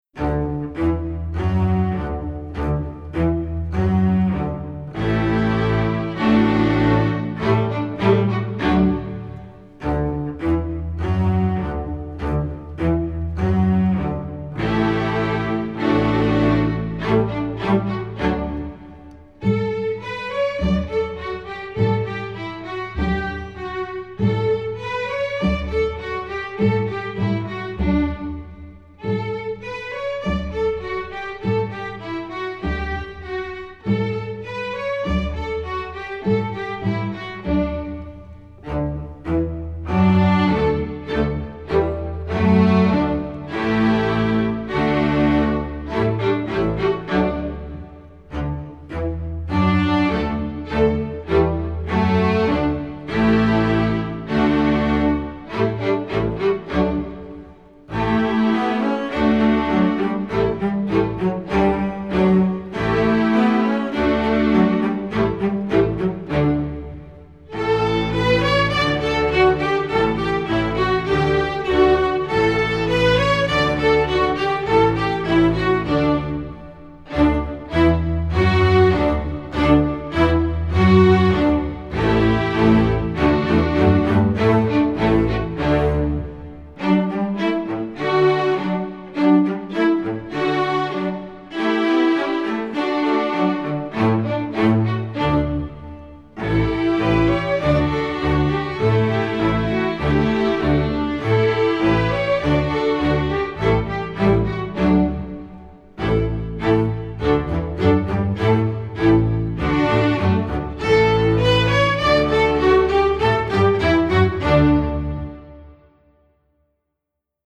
Instrumentation: string orchestra
instructional, children
Piano accompaniment part: